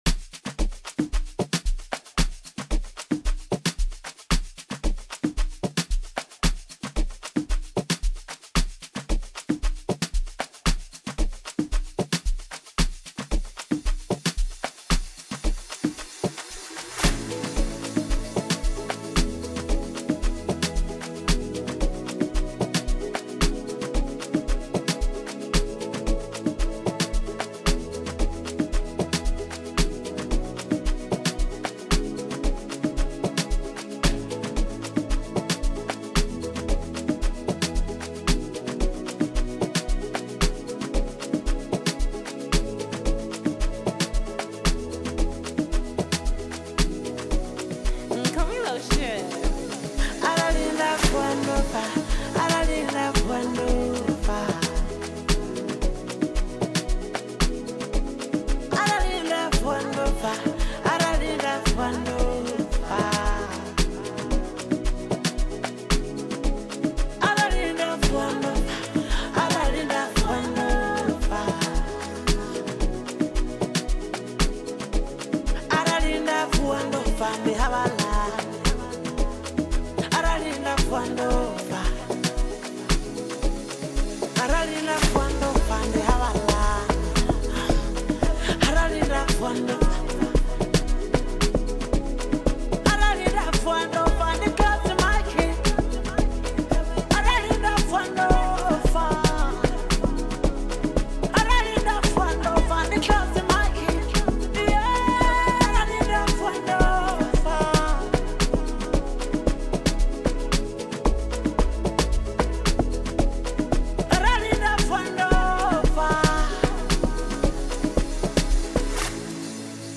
AmapianoMusic